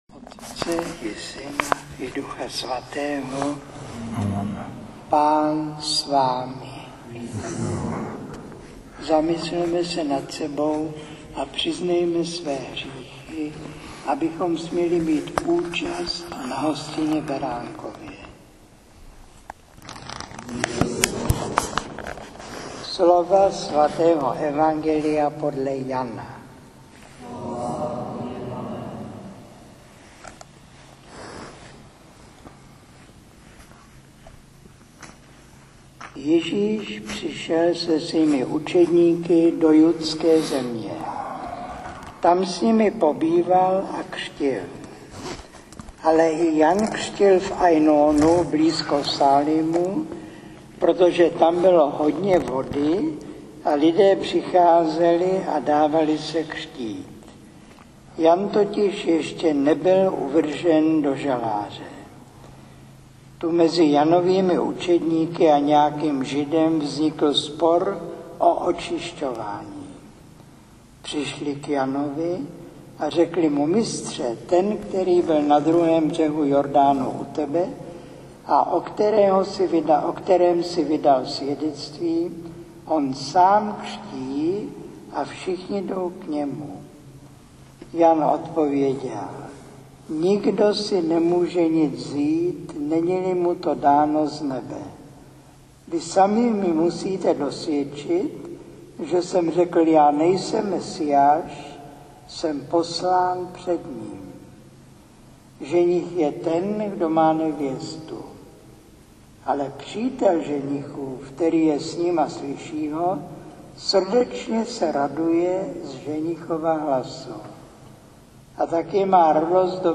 7. kázání (7.5 min.)